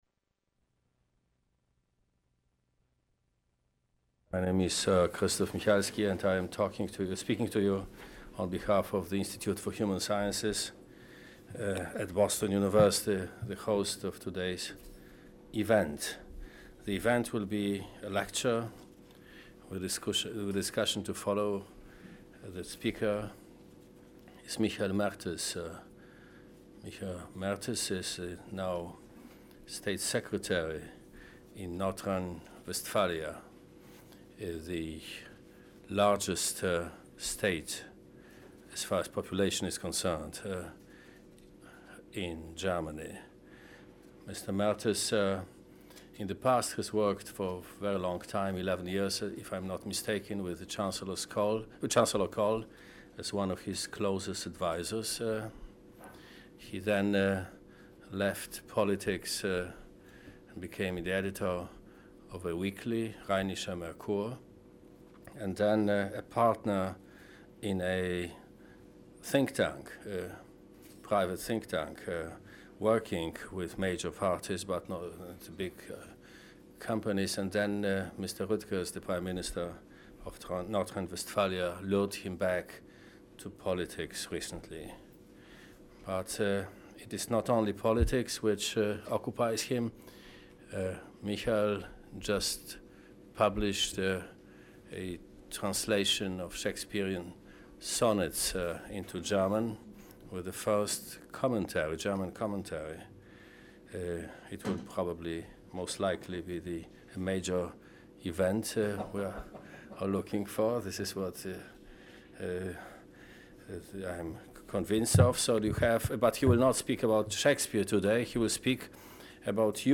Audio recording of the talk